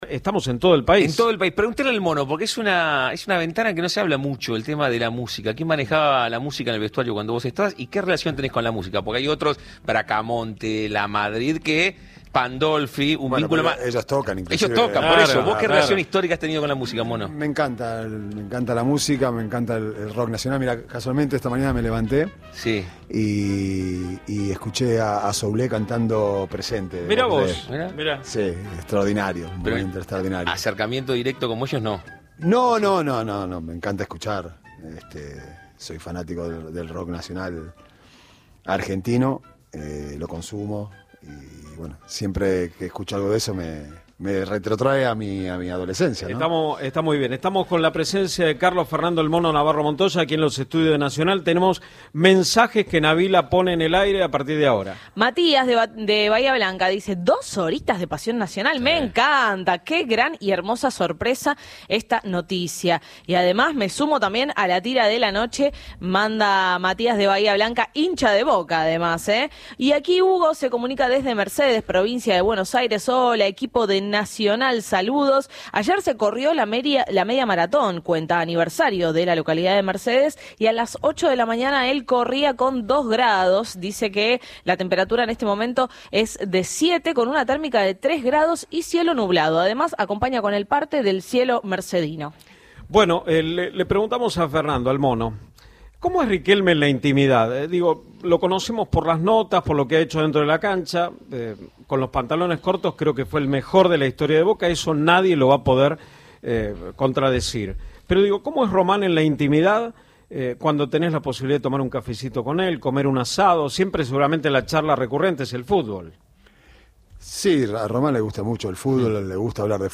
El ex arquero de Boca Juniors Carlos "Mono" Navarro Montoya, quien estuvo dirigiendo hasta hace poco e Santamarina de Tandil, visitó el estudio de Nacional […]